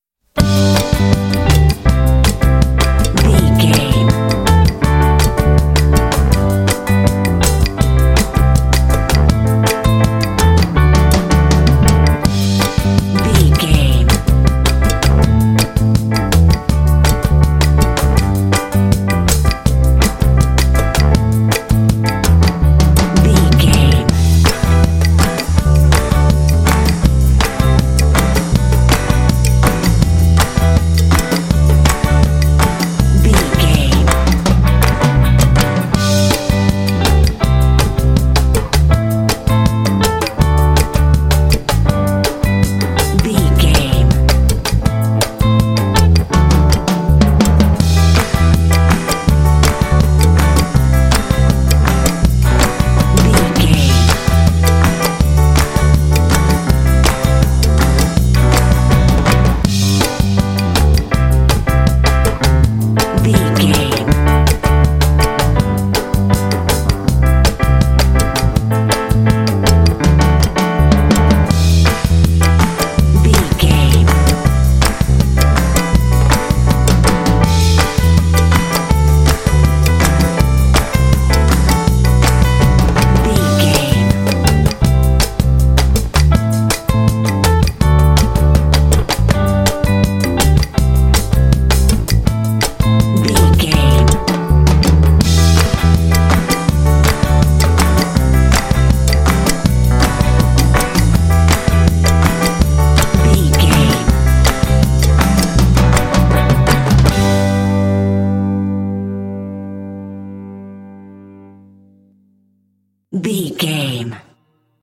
This fun and upbeat track has an energetic Latin groove.
Ionian/Major
cheerful/happy
groovy
uplifting
driving
piano
bass guitar
percussion
drums
latin jazz